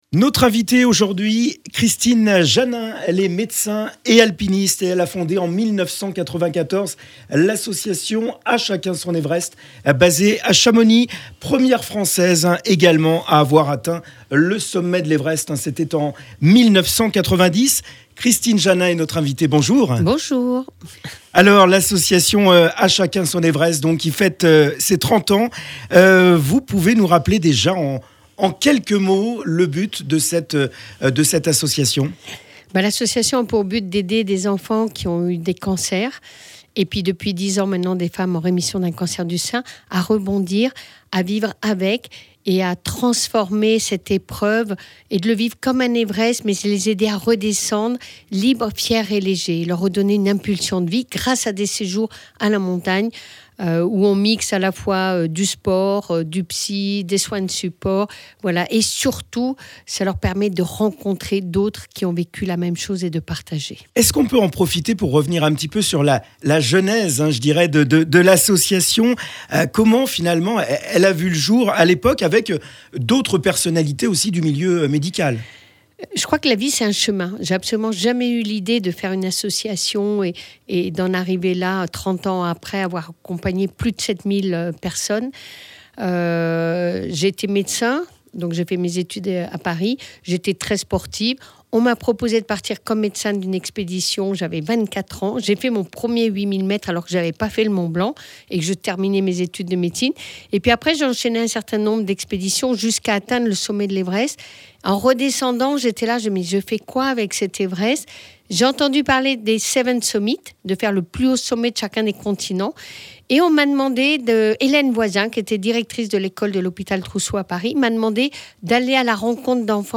L'interview complète est à retrouver ci-dessous.